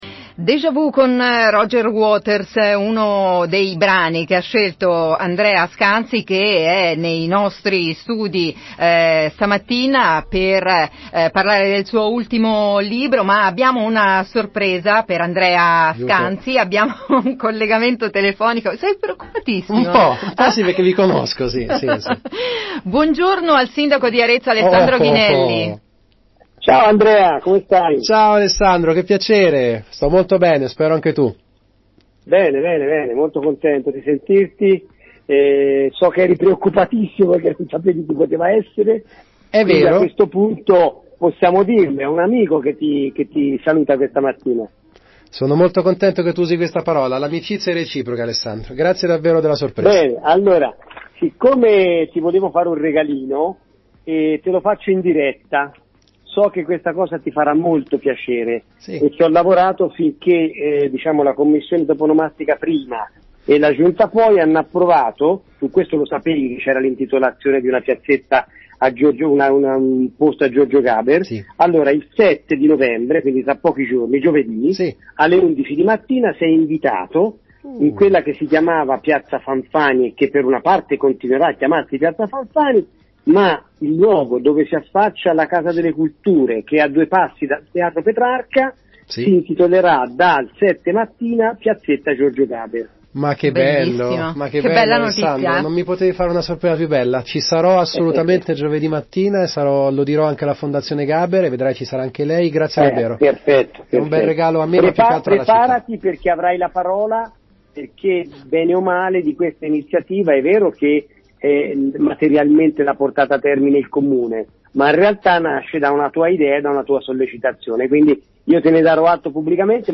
Audio dell’annuncio a sorpresa  del Sindaco Ghinelli  a Andrea Scanzi in diretta su RadioFly della inaugurazione della Piazzetta Giorgio Gaber il 7 Novembre ore 11.00 ad Arezzo
Ghinelli_Scanzi-1.mp3